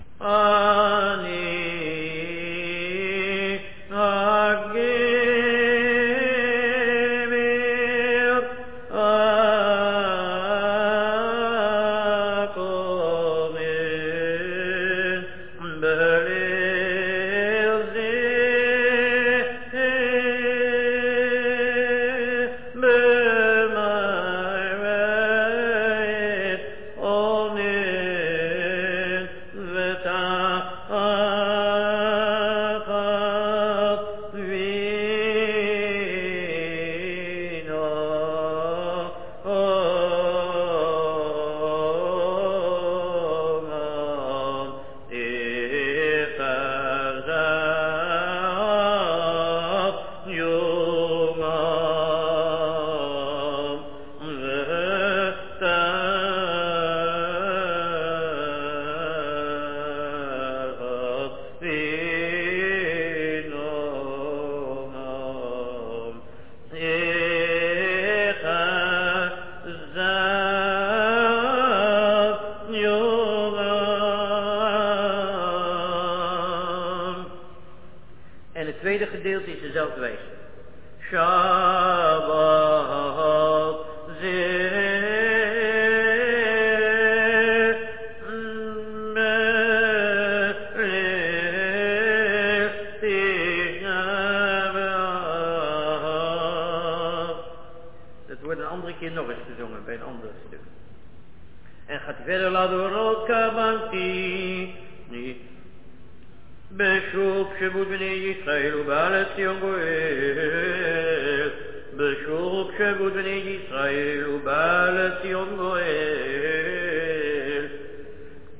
Mind that most recordings were made by non professional Chazzanim and thus are not 100% according to the sheet music.